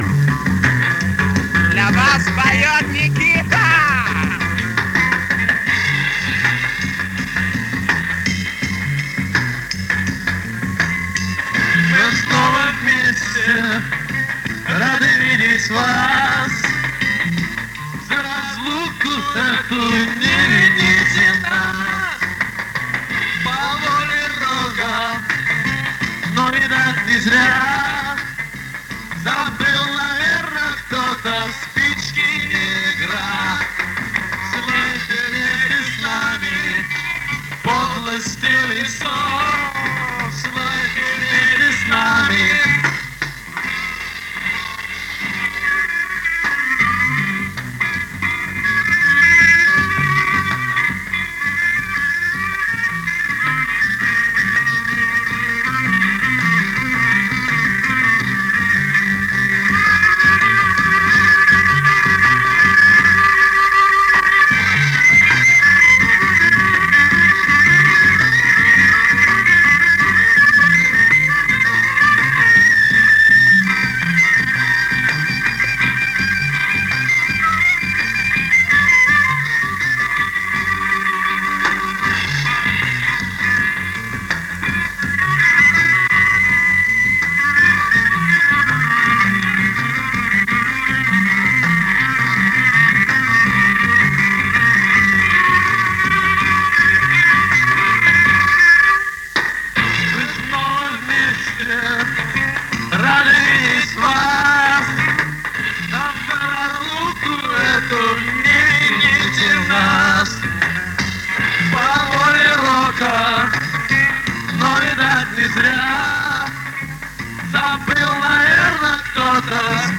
Наводит на эти мысли представленная здесь концертная запись.
Концерт 71-го года